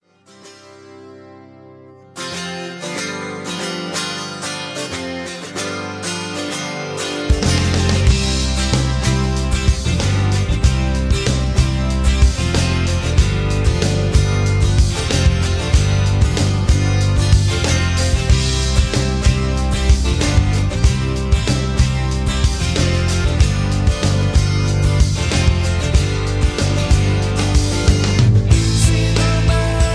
Tags: karaoke , backingtracks , soundtracks , rock